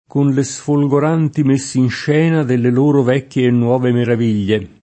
kon le Sfolgor#nti meSSinš$na delle l1ro v$kkLe e nnU0ve merav&l’l’e] (Civinini) — oggi meno com., salvo forse nel pl., messa in scena [